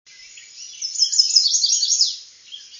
Chestnut-sided Warbler
Indian Head Point, Mohican Outdoor Center, Delaware Water Gap, 6/4/01 (49kb).  Song variant with descending suffix, "I see you."  With Great-crested Flycatcher in background.
warbler_chestnut-sided_768.wav